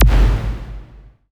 Index of /90_sSampleCDs/Optical Media International - Sonic Images Library/SI2_SI FX Vol 5/SI2_Gated FX 5